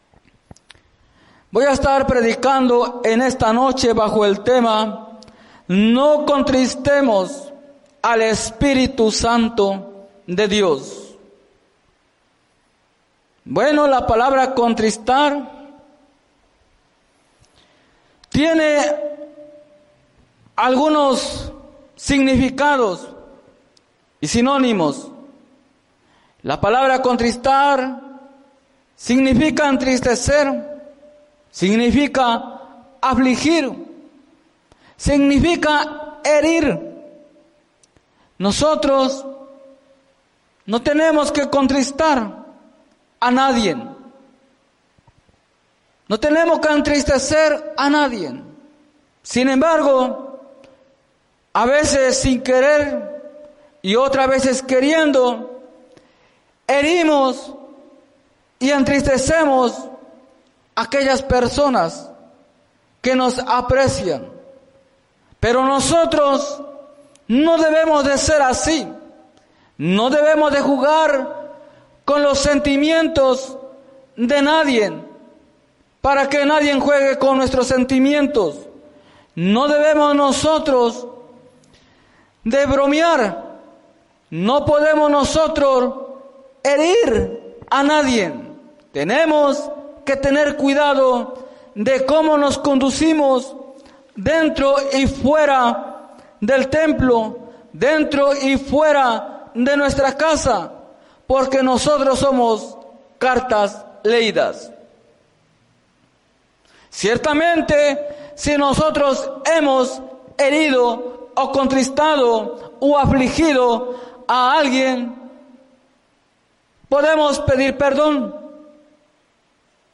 Mensaje
en la Iglesia Misión Evangélica en Norristown, PA